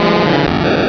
sovereignx/sound/direct_sound_samples/cries/magikarp.aif at 6b8665d08f357e995939b15cd911e721f21402c9